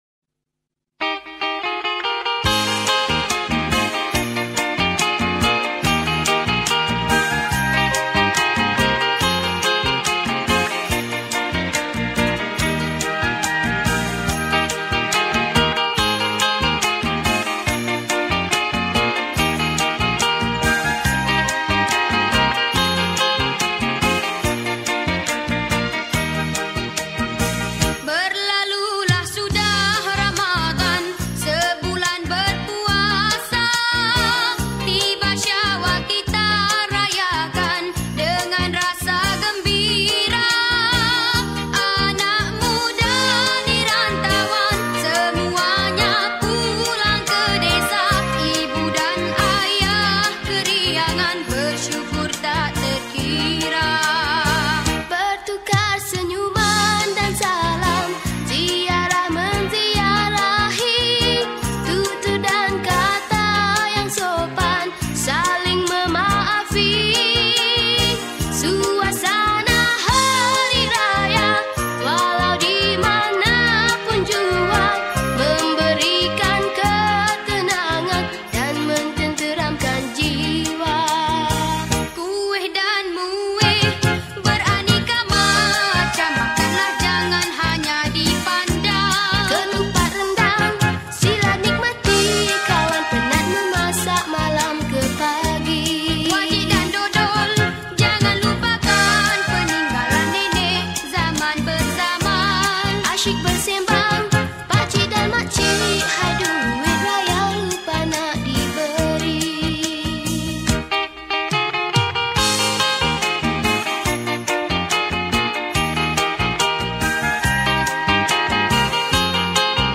Lagu Hari Raya
Skor Angklung